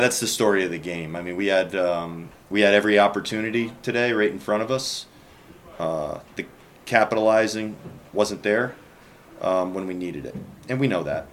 Twins manager Rocco Baldelli says they left too many runners on base in this one.